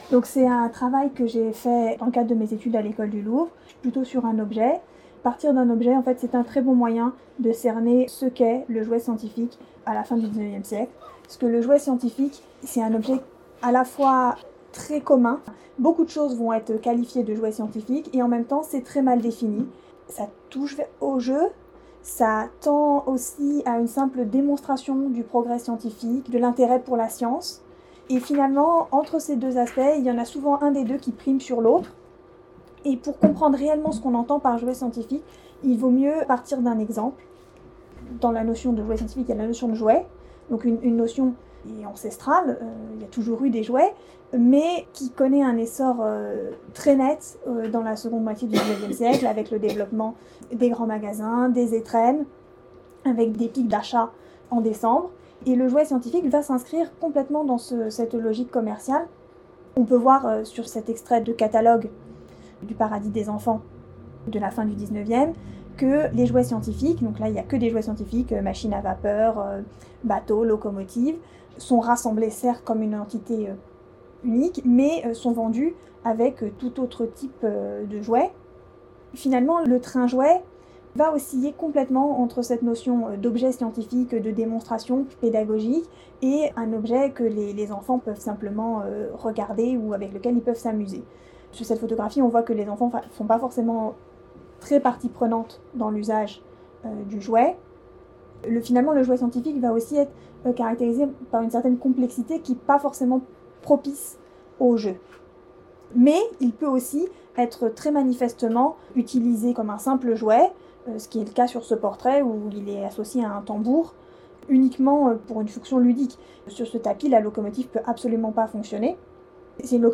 Conférence du samedi 14 mars 2026